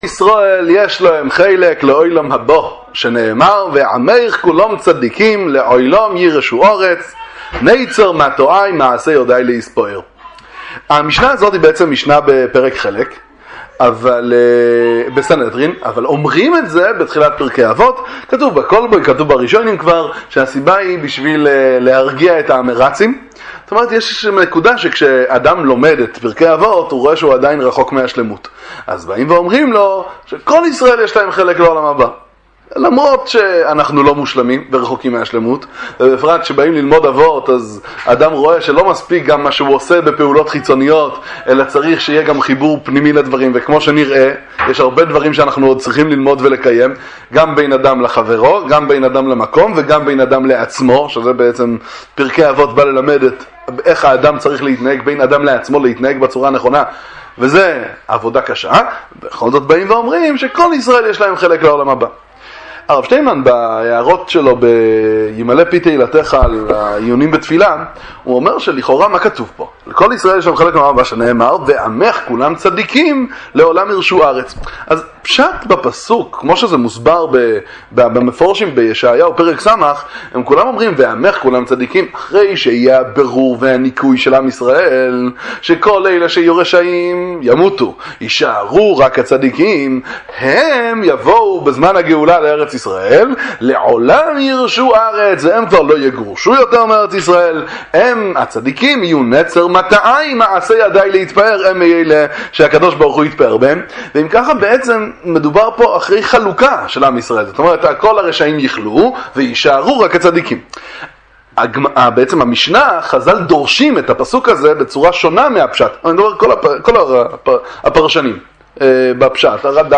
דבר תורה קצר על פרקי אבות – משנת כל ישראל – הנקודה של הצדיקות שיש בכל יהודי